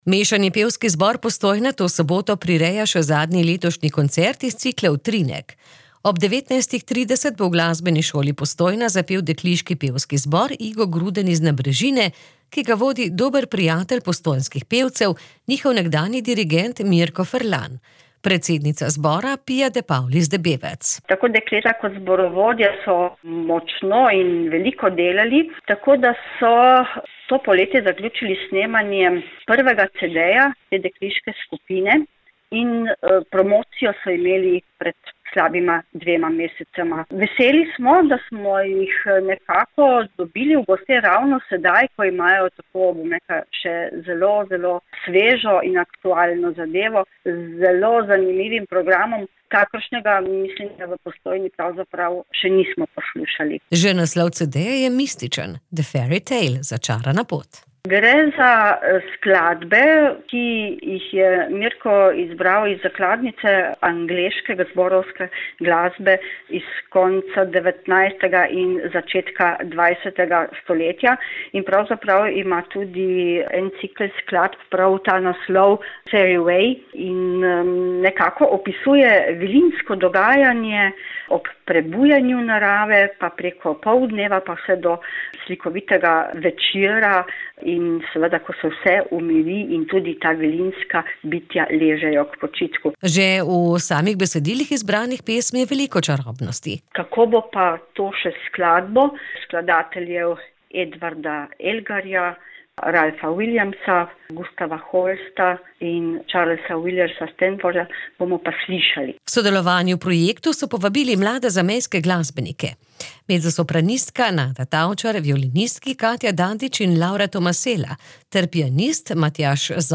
p364-82-tokrat-v-gosteh-zbor-z-nabrezine.mp3